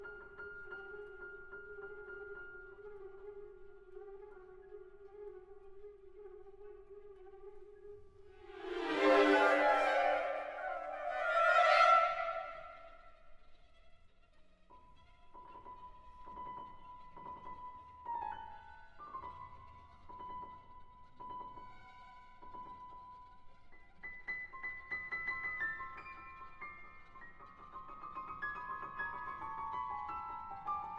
"enPreferredTerm" => "Musique orchestrale"